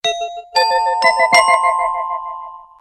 Doorbell.wav